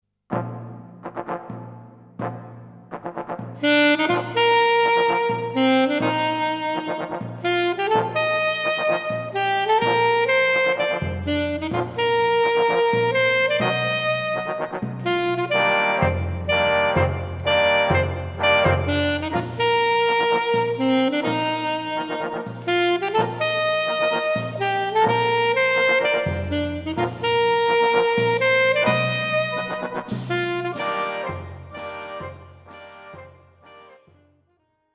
アルトサックス+ピアノ